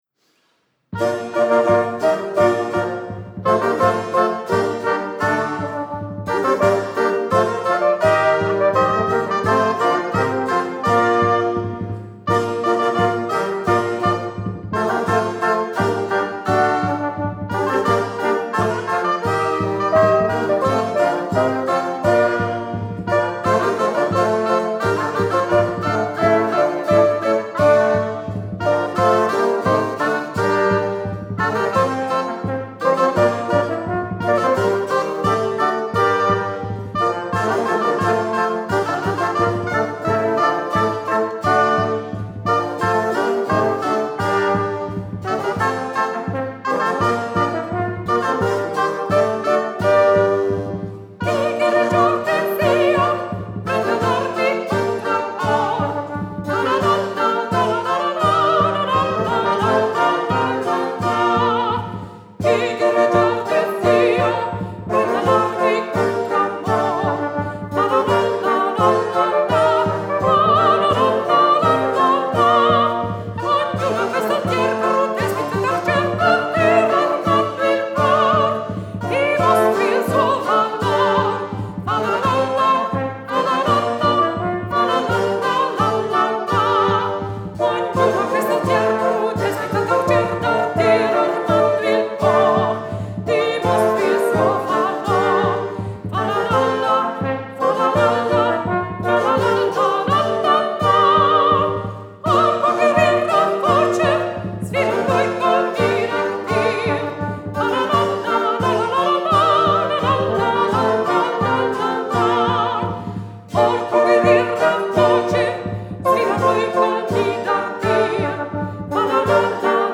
nella Chiesa di San Filippo Neri  (Savona).
Sono state utilizzate esclusivamente copie di strumenti rinascimentali.
Soprano
Cornetto
Bombarda
Trombone Contralto
Trombone Tenore
Flauto e Dulciana
Percussioni
Clavicembalo